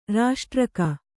♪ raṣṭraka